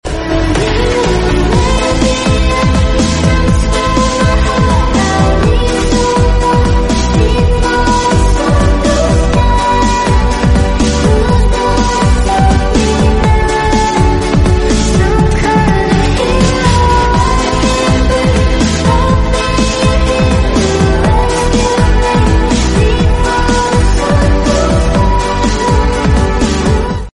ready cek sound karnaval Dawuhan sound effects free download